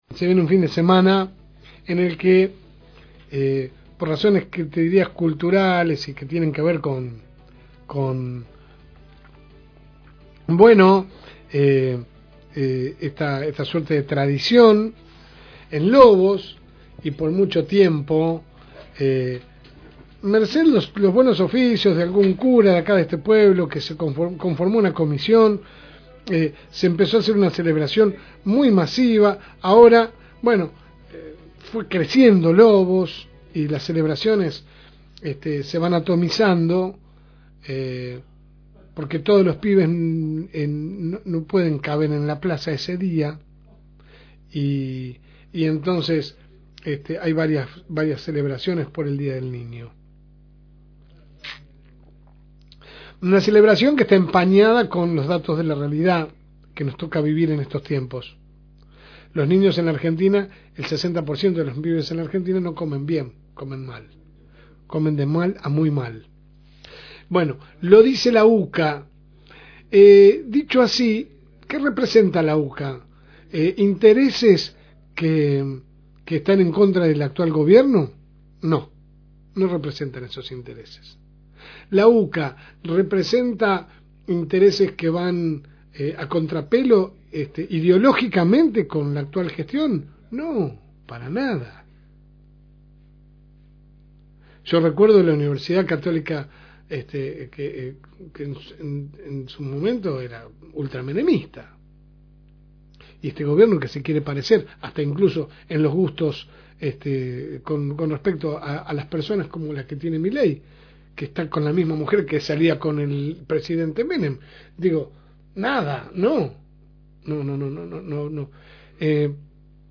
AUDIO – Editorial de La Segunda Mañana